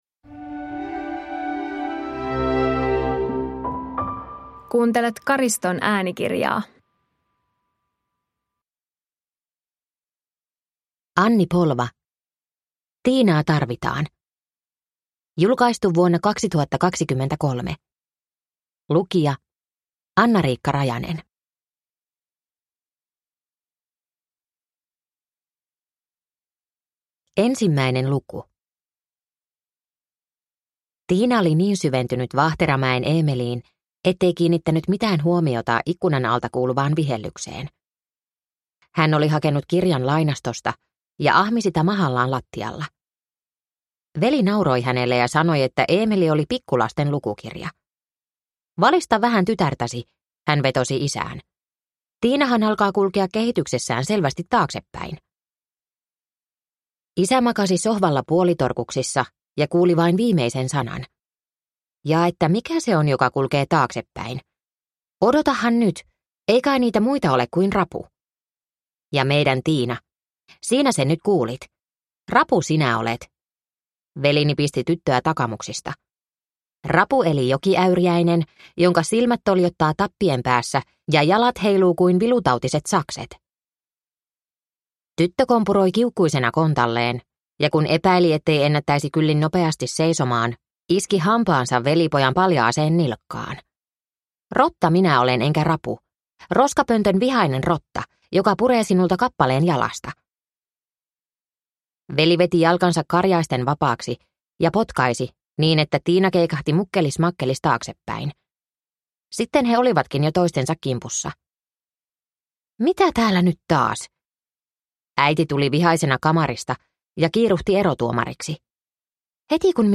Tiinaa tarvitaan (ljudbok) av Anni Polva